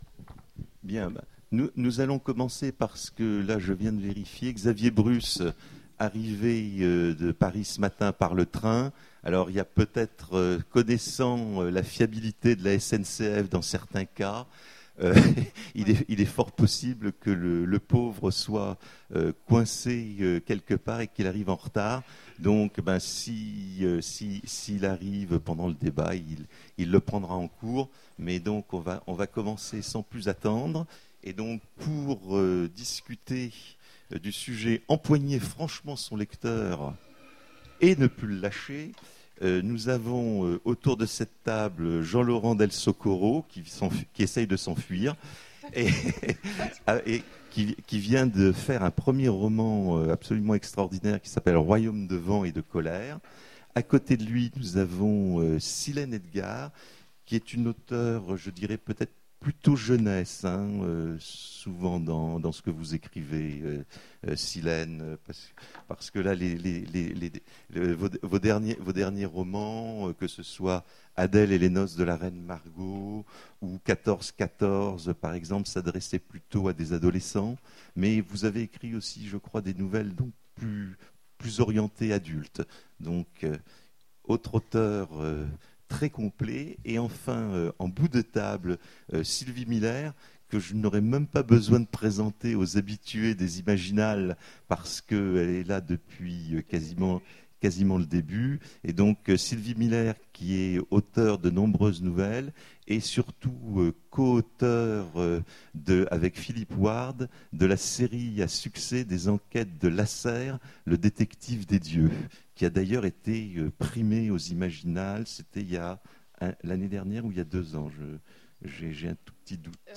Imaginales 2015 : Conférence Empoigner fermement son lecteur...